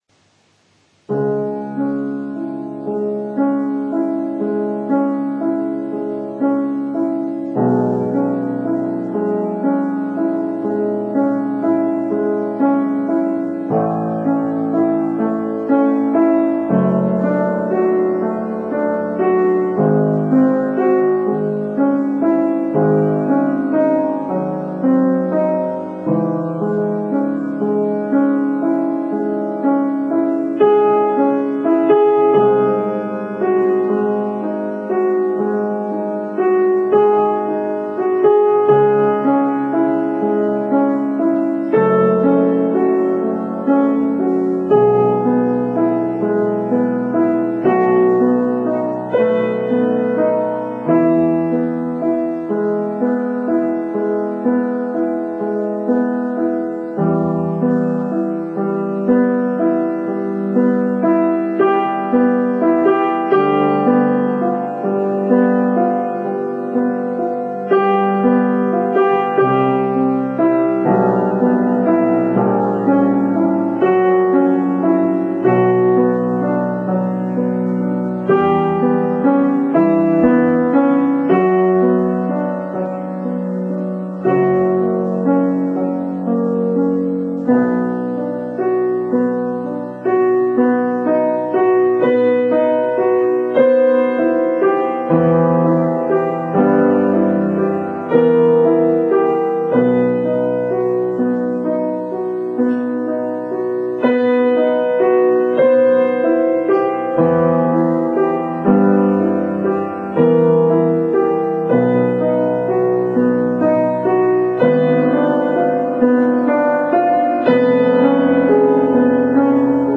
ピアノのしらべ：ベートーヴェン作曲ソナタ第14番「月光」
静かで幻想的な曲なので、秋の夜に聴くのがとても似合います。